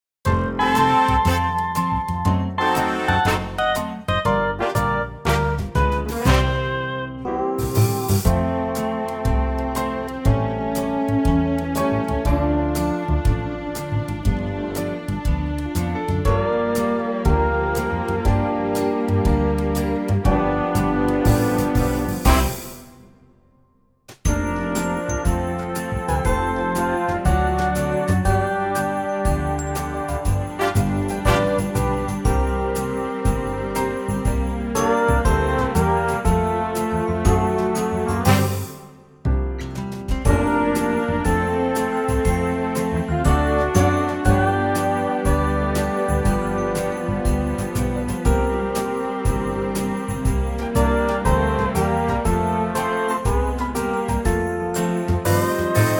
Unique Backing Tracks
key - F - vocal range - B to C